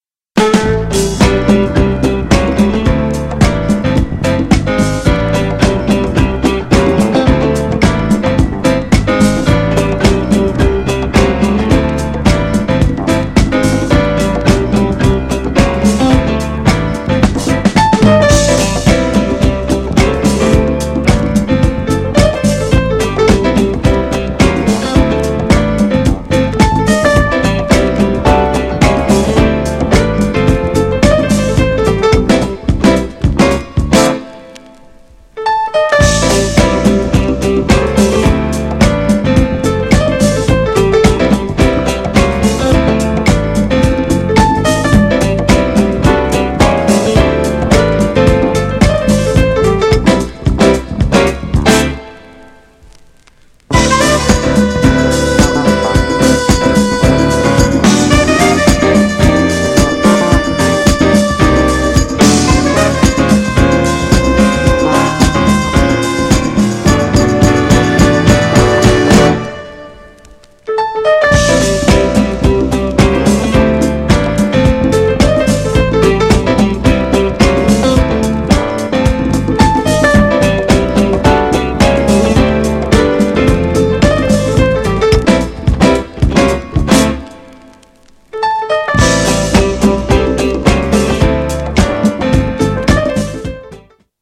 GENRE Dance Classic
BPM 91〜95BPM